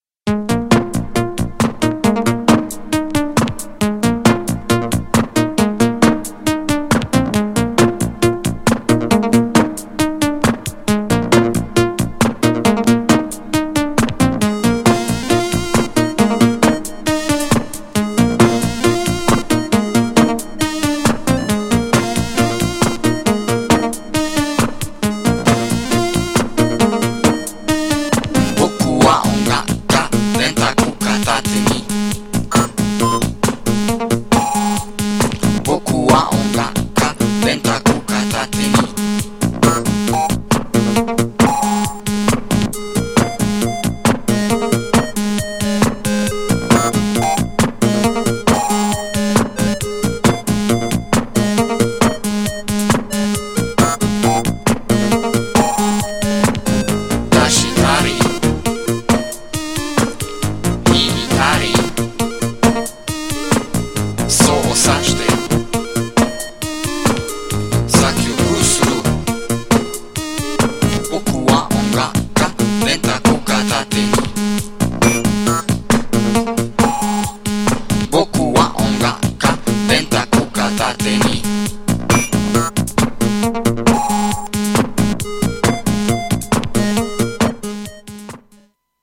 GENRE Dance Classic
BPM 121〜125BPM
エレクトロ
空間的